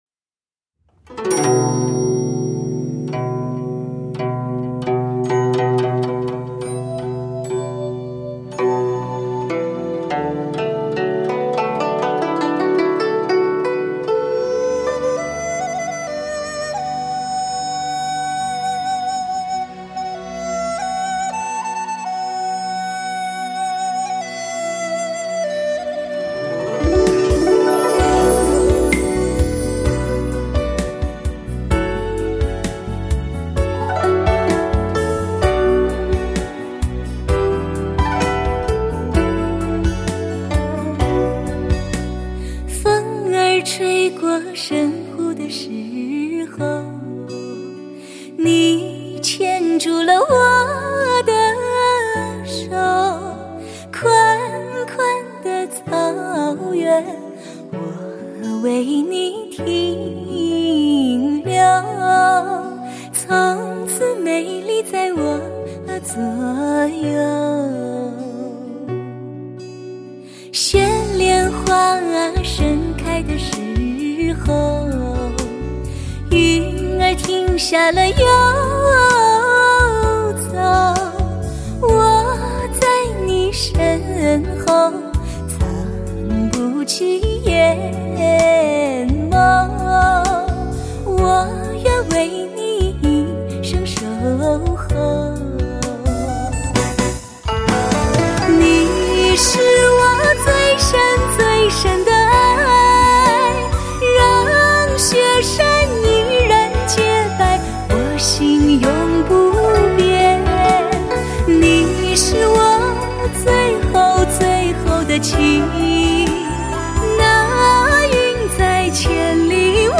类型: 汽车音乐
专有HDSTS Magix Mastering母带制作，STS Magix Virtual Live高临场感CD！